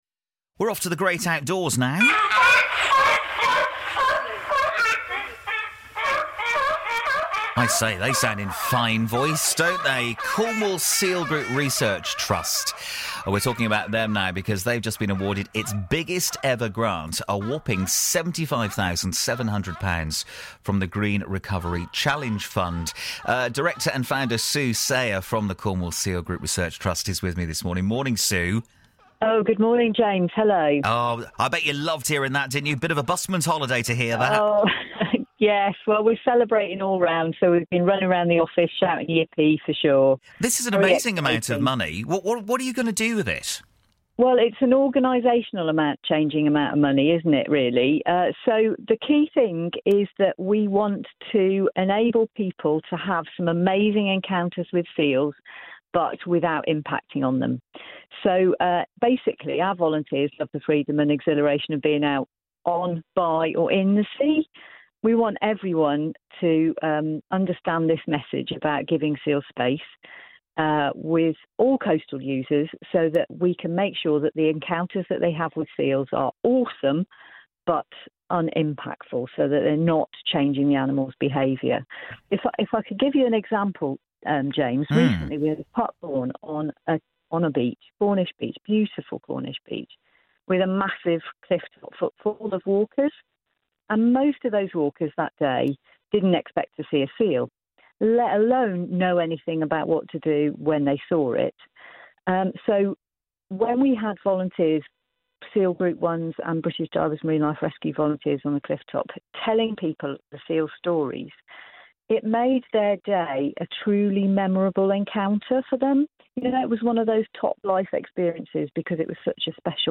interview about the grant on BBC Radio Cornwall